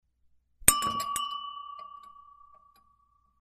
Звуки чоканья бокалов